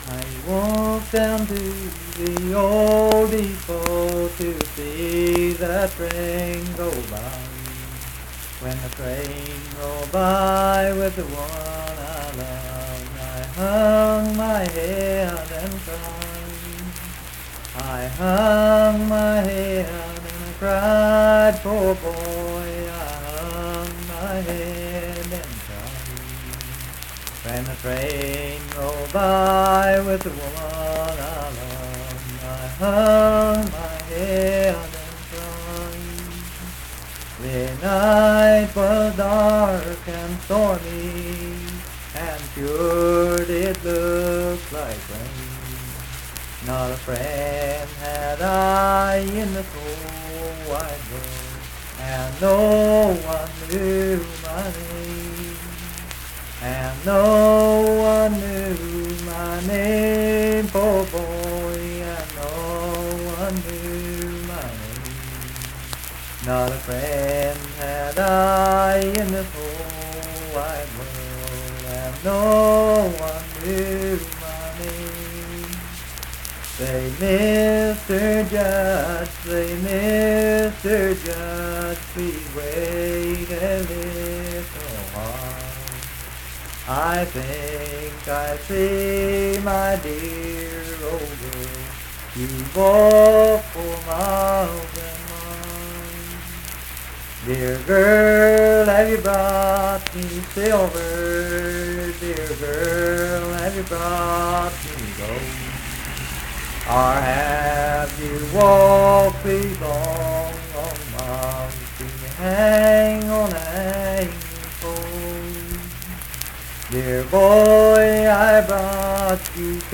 Unaccompanied vocal music performance
Verse-refrain 8(4).
Voice (sung)